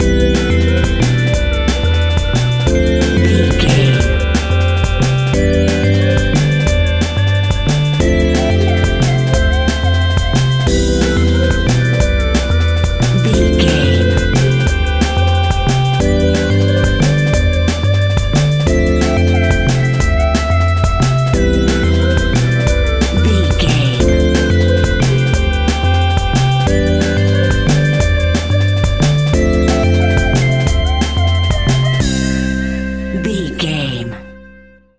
Ionian/Major
Fast
energetic
hypnotic
frantic
drum machine
synthesiser
electronic
sub bass